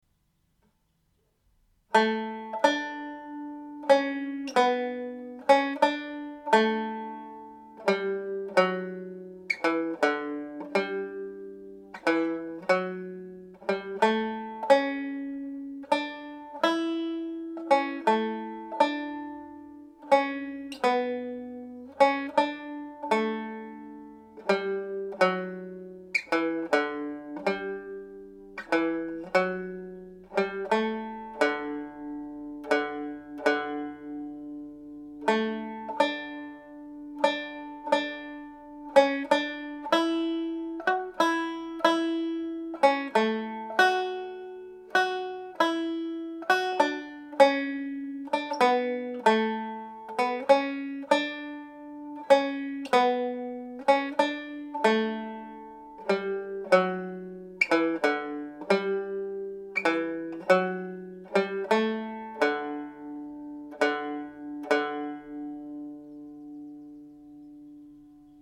This is a beautiful slow piece of music written by Turlough O’Carolan.
Planxty Irwin played slowly